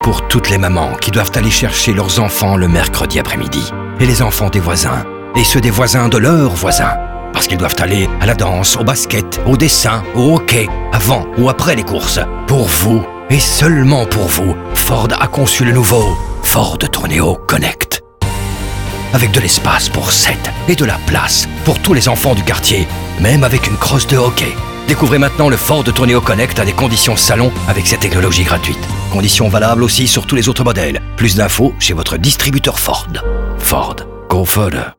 Ogilvy célèbre les héros du quotidien pour le lancement du nouveau Ford Tourneo Connect, une voiture familiale spacieuse qui peut embarquer jusqu’à 7 passagers. L’agence a ainsi créé deux spots radio épiques qui rendent hommage à toutes les mamans et tous les papas qui, chaque week-end et mercredi après-midi, passent leur temps à rouler d’un point à un autre.